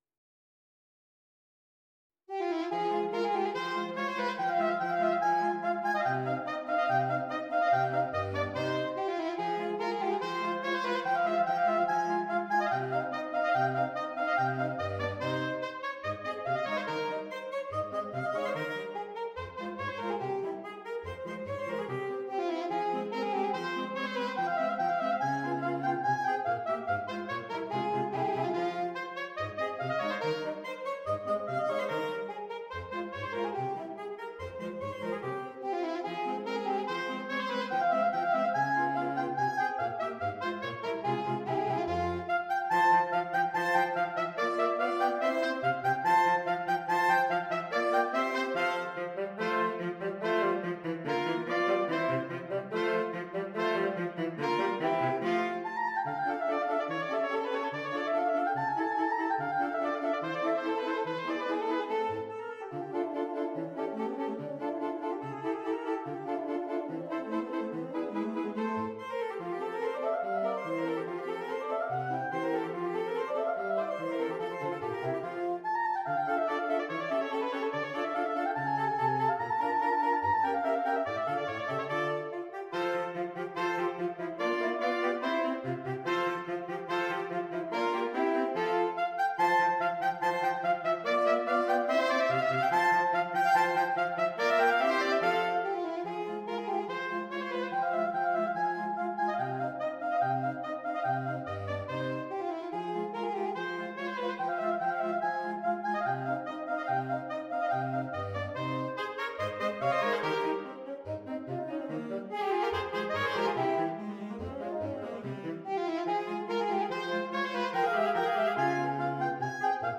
Saxophone Quartet (SATB)